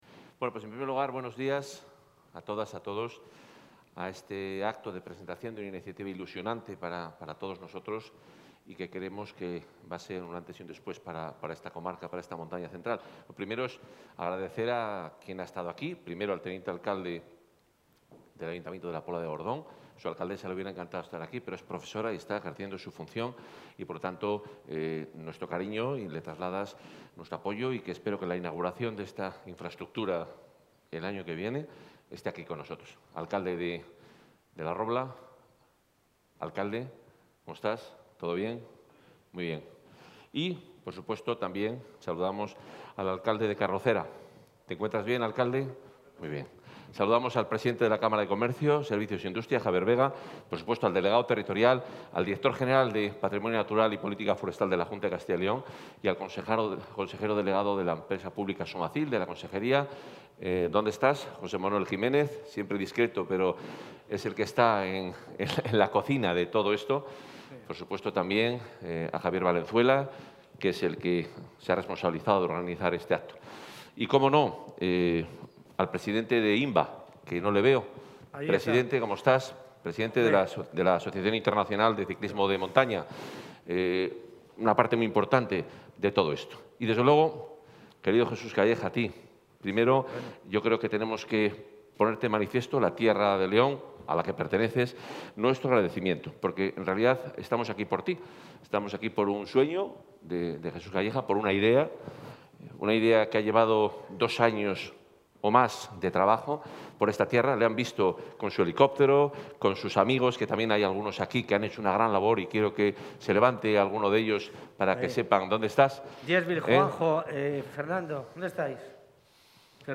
Intervención del consejero de Fomento y Medio Ambiente.
El consejero de Fomento y Medio Ambiente, Juan Carlos Suárez-Quiñones, ha presentado esta mañana en el Centro del Clima de la localidad leonesa de La Vid de Gordón el proyecto denominado 'Zona Alfa León', que aúna naturaleza, turismo activo y desarrollo rural. Supondrá una inversión de más de 1,2 millones de euros y albergará circuitos homologados para la práctica con bicicletas de montaña.